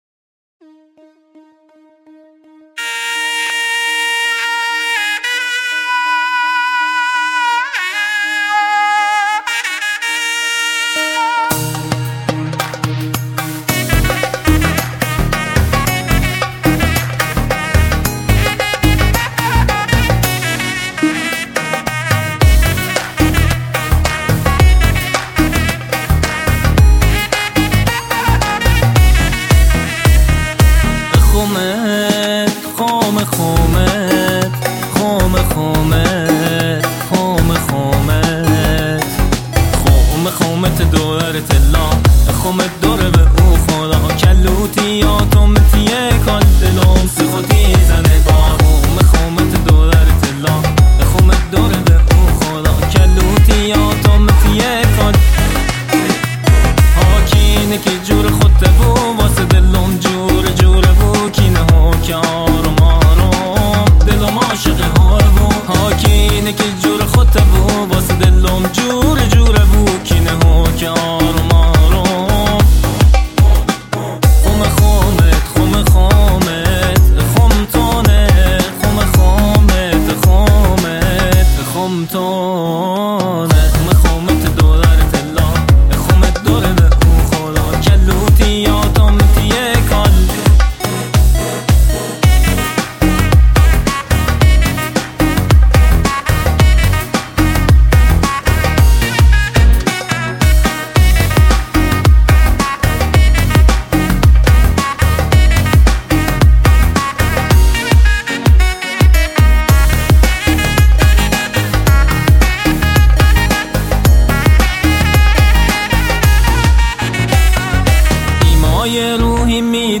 آهنگ شاد لری